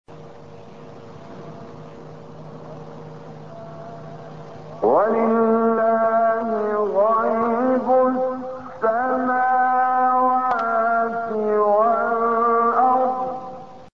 گروه شبکه اجتماعی: فرازهای صوتی از کامل یوسف البهتیمی که در مقام بیات اجرا شده است، می‌شنوید.